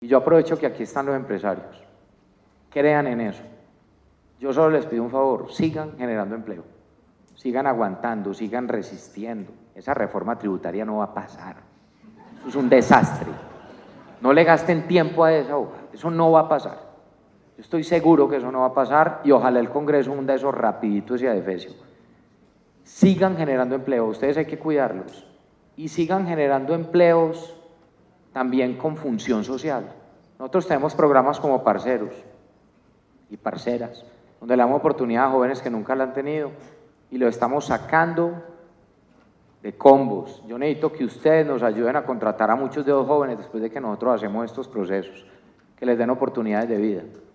Un llamado a continuar generando empleo y apoyar los programas sociales y de formación a jóvenes que viene poniendo en marcha la Alcaldía de Medellín, hizo el alcalde Federico Gutiérrez a líderes empresariales del país durante el Foro Forbes Medellín de Economía y Negocios, realizado en la ciudad con la presencia de representantes del sector público y privado.
Declaraciones-del-alcalde-de-Medellin-Federico-Gutierrez-03-3.mp3